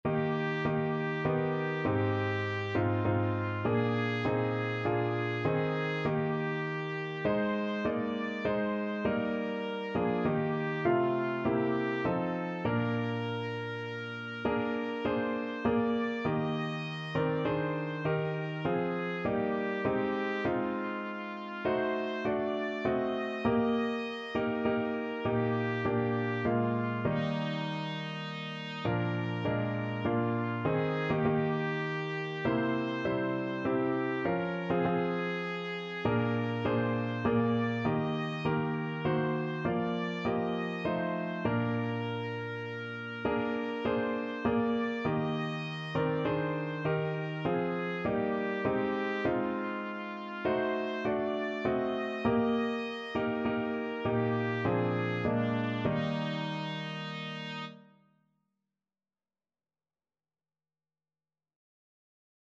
3/4 (View more 3/4 Music)
D5-Eb6
Classical (View more Classical Trumpet Music)